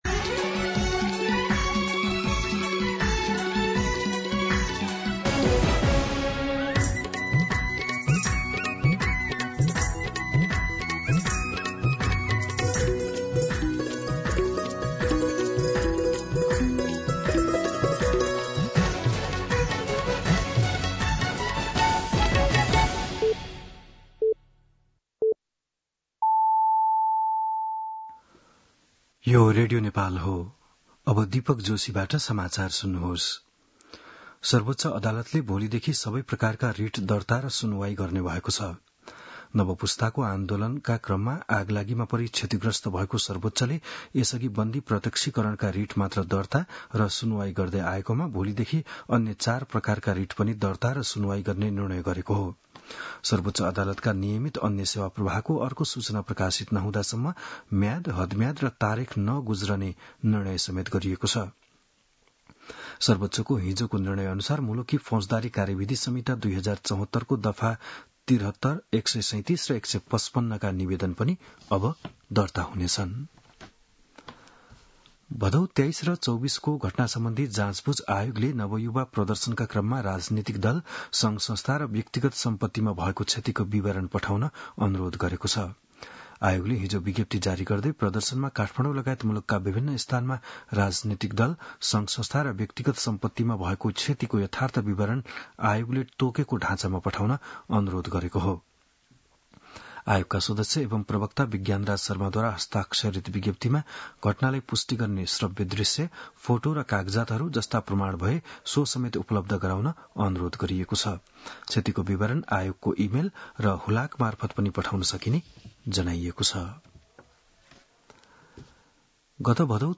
बिहान ११ बजेको नेपाली समाचार : १८ पुष , २०२६
11-am-Nepali-News-4.mp3